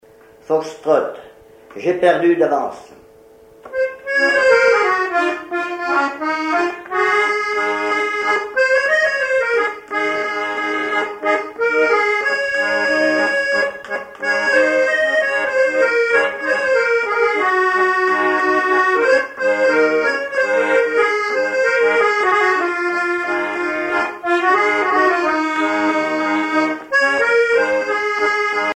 accordéon(s), accordéoniste
danse : fox-trot
Répertoire à l'accordéon chromatique
Pièce musicale inédite